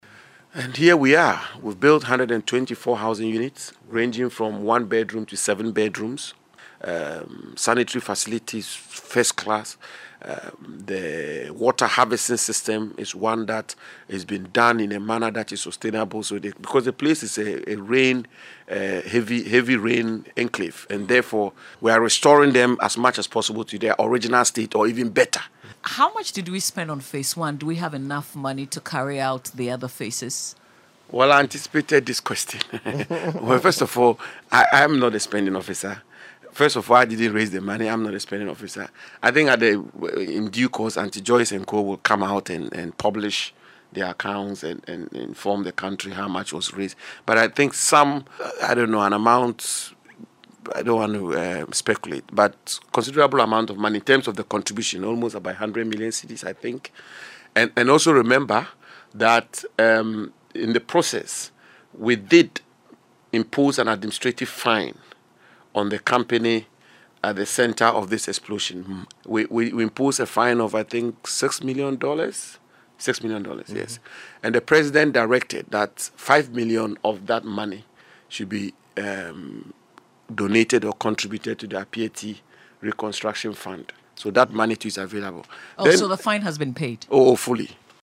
Lands Minister, Samuel Abu Jinapor told JoyNews that the new homes offer the inhabitants a lot of amenities to improve their lives.